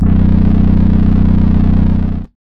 SGLBASS  3-R.wav